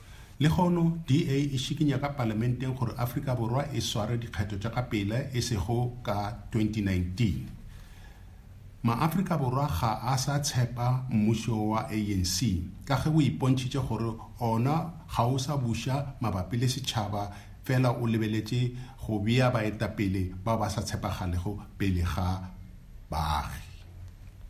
Issued by Zakhele Mbhele MP and Sejamothopo Motau MP – DA Shadow Minister of Police and DA Shadow Minister of the Presidency, Planning, Monitoring and Evaluation
Note to editors: Attached please find sound bites in
Sejamothopo-Motau-Sepedi.mp3